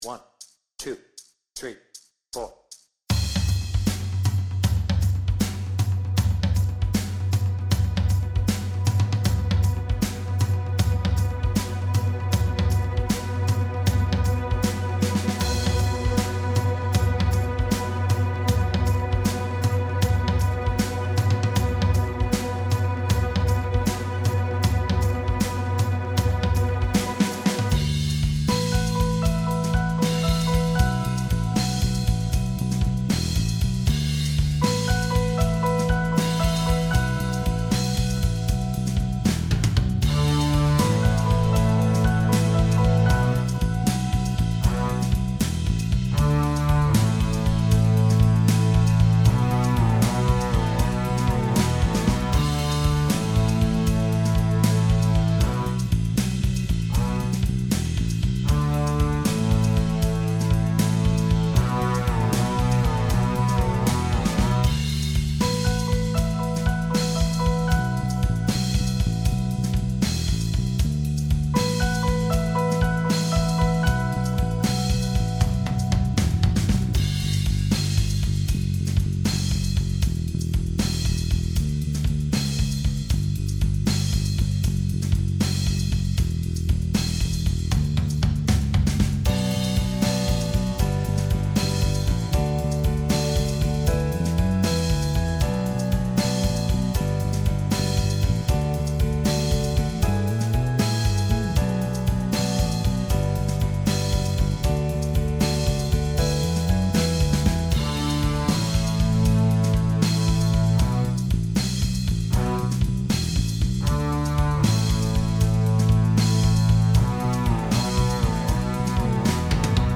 With vocals